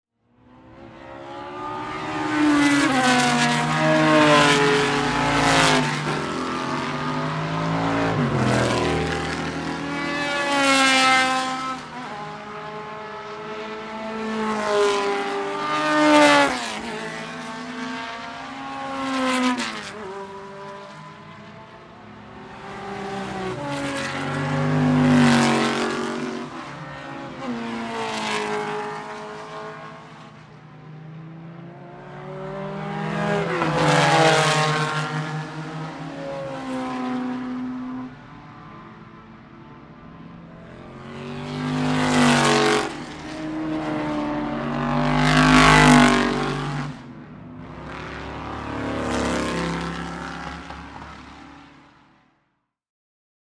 Vorbeifahrende Protoypen und GT-Fahrzeuge der World Sportscar Master Serie - AvD Oldtimer Grand Prix 2012